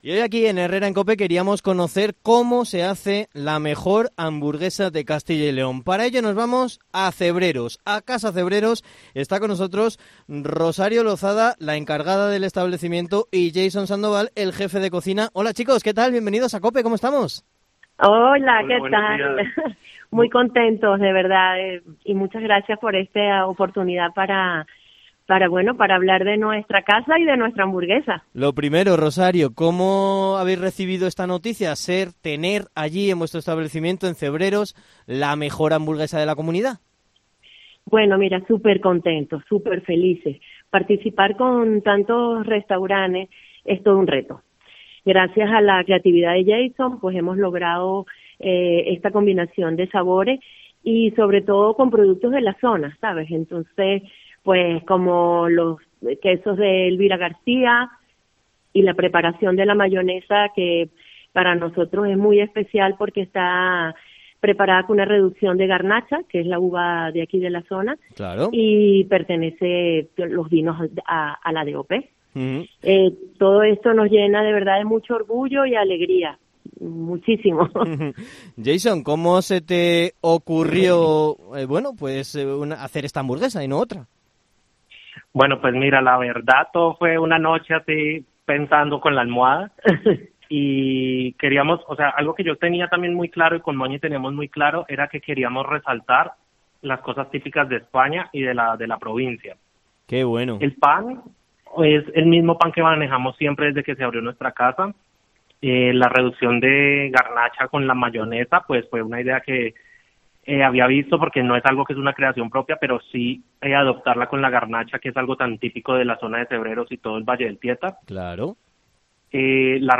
Entrevista / Casa Cebreros, mejor hamburguesa de Castilla y León